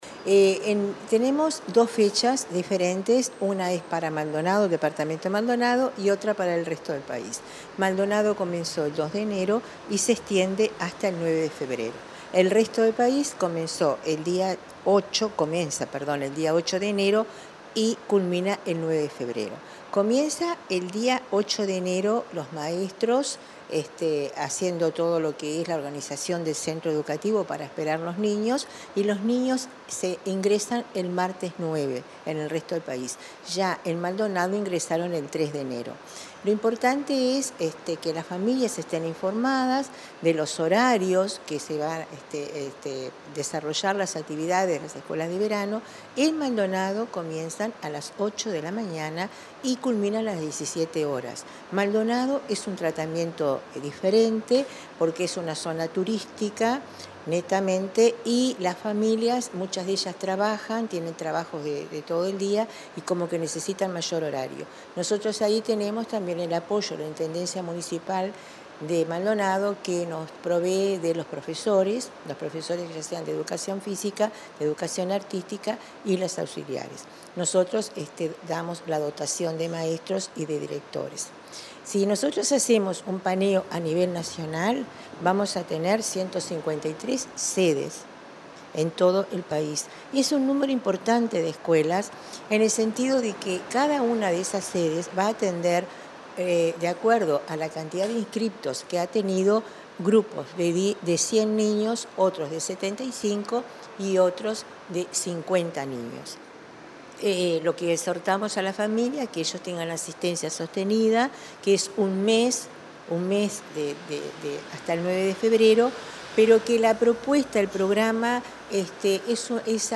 Entrevista a la directora de Educación Inicial y Primaria, Olga de las Heras